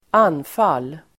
Uttal: [²'an:fal:]